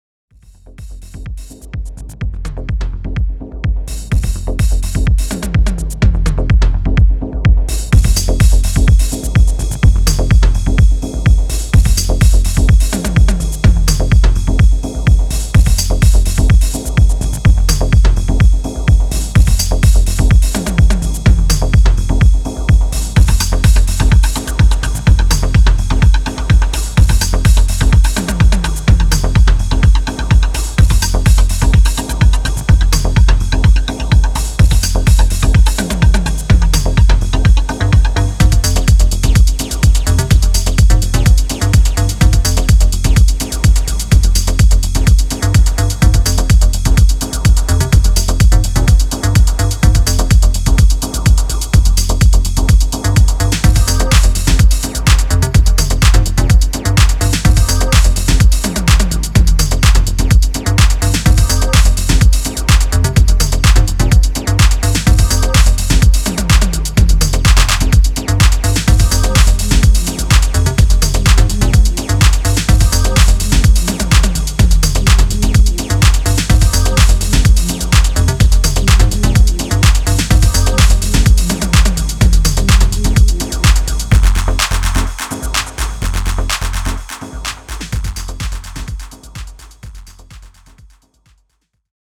ソリッドなディープ・ハウス群を展開しています。